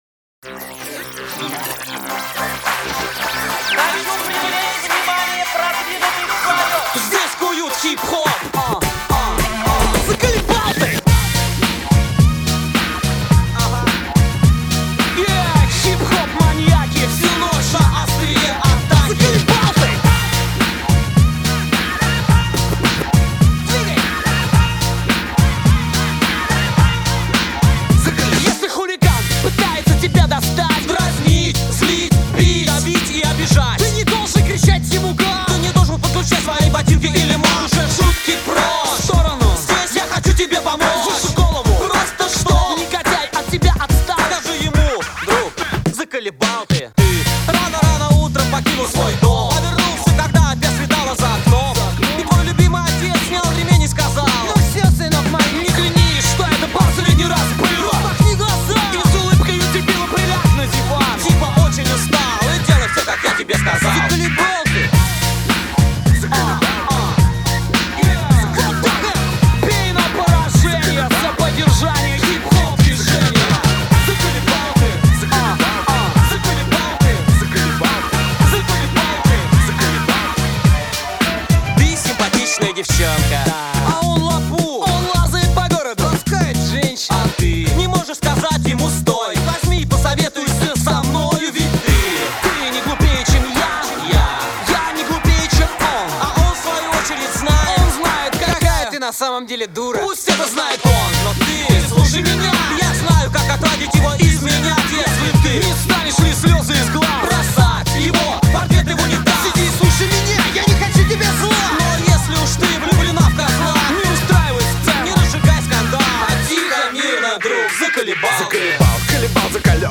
Жанр: Rap & Hip-Hop